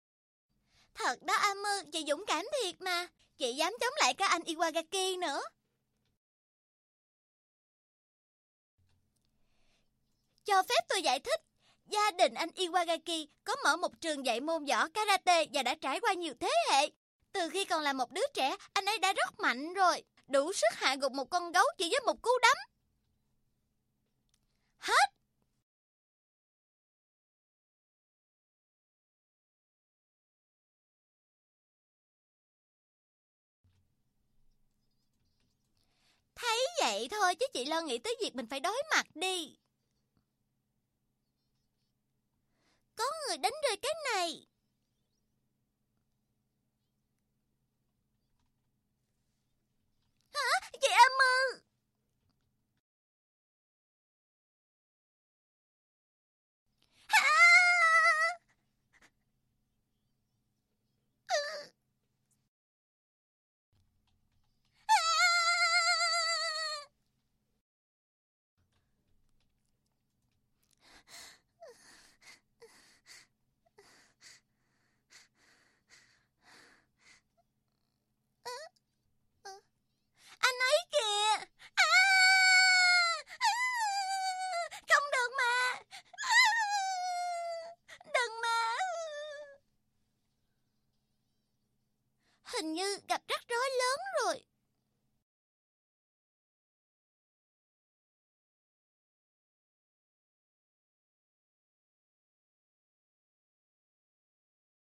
• 4Vietnamese Female No.4
Game Characters